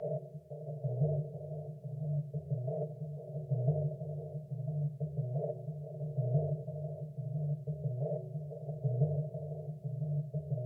惊悚 " 恐怖的声音
标签： 环境 邪恶 黑暗 怪异气氛 令人毛骨悚然 恐怖 吓人
声道立体声